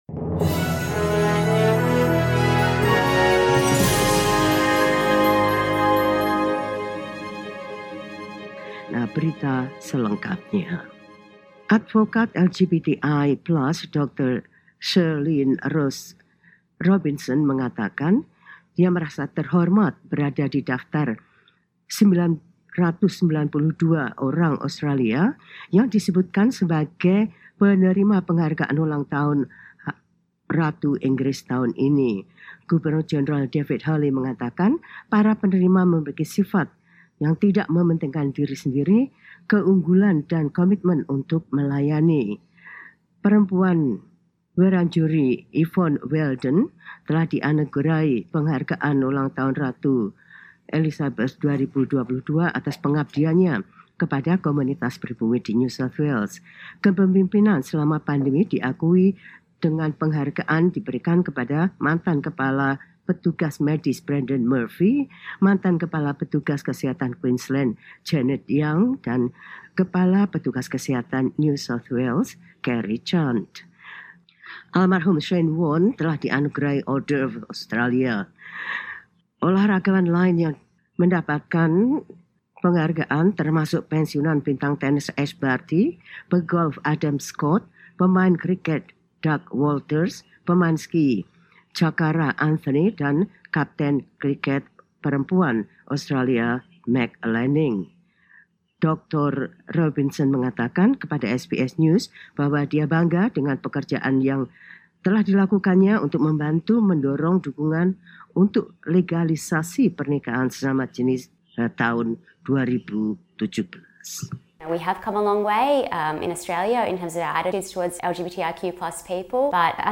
SBS Radio news in Indonesian Source: SBS